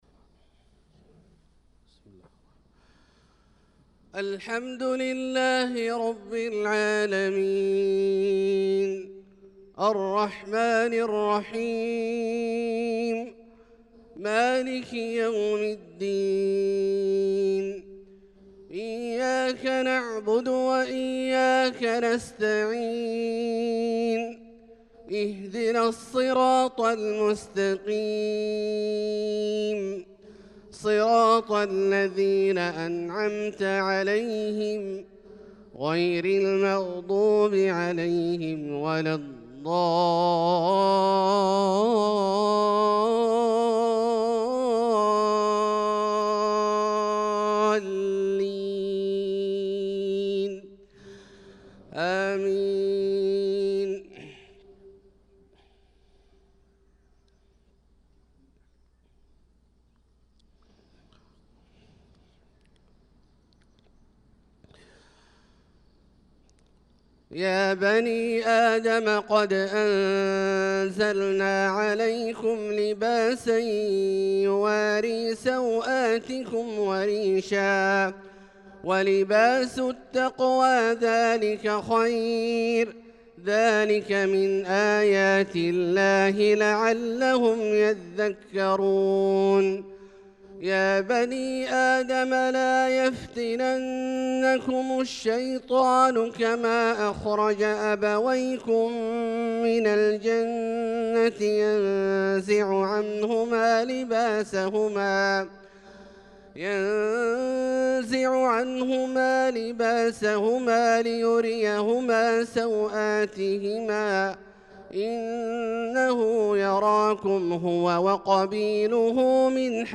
صلاة الفجر للقارئ عبدالله الجهني 29 شوال 1445 هـ